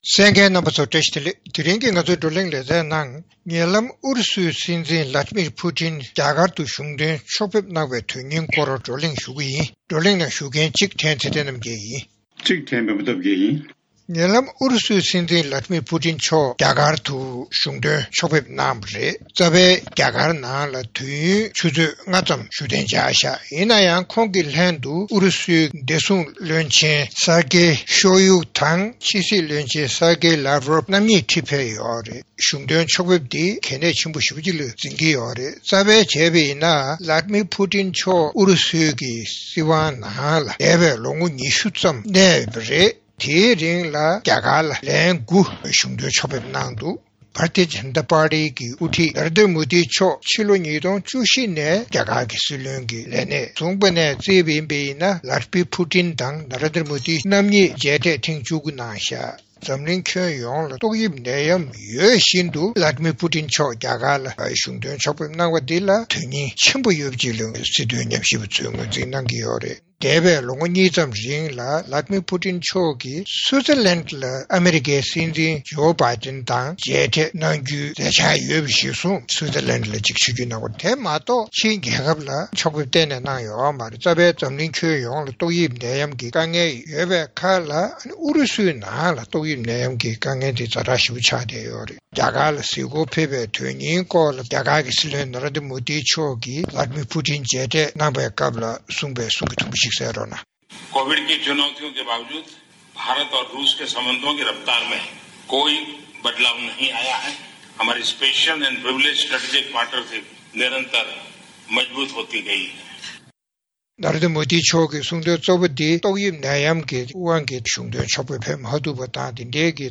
རྩོམ་སྒྲིག་པའི་གླེང་སྟེགས་ཞེས་པའི་ལེ་ཚན་ནང་། ཉེ་ལམ་ཨུ་རུ་སུའི་སྲིད་འཛིན་Vladimir Putin རྒྱ་གར་དུ་གཞུང་དོན་ཕྱོགས་ཕེབས་གནང་བའི་དོན་སྙིང་སྐོར་བགྲོ་གླེང་གནང་བ་གསན་རོགས་གནང་།